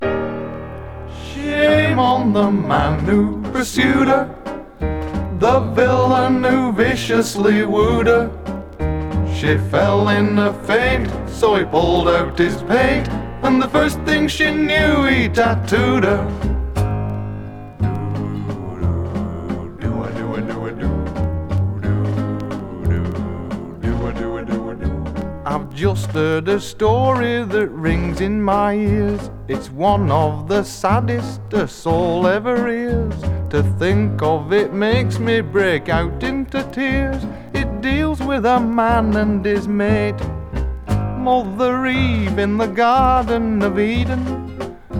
Pop, Rock, Vocal　UK　12inchレコード　33rpm　Stereo